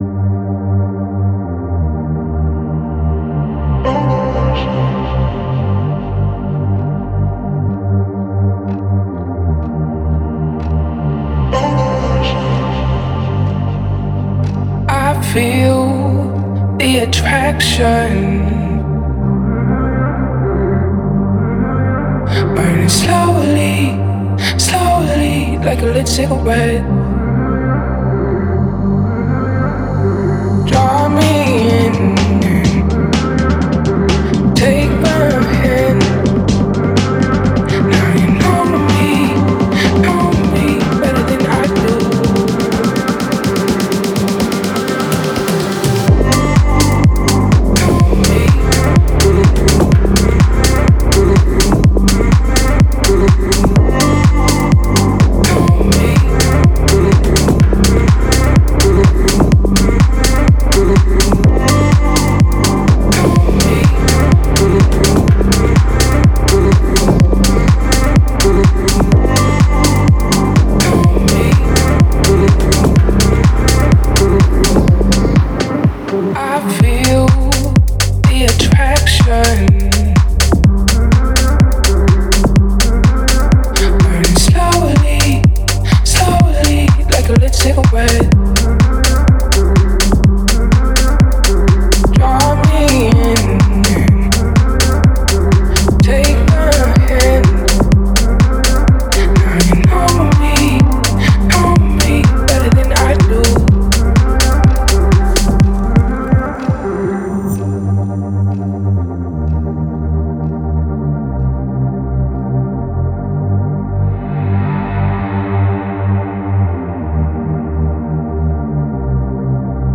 это мощная композиция в жанре R&B с элементами хип-хопа.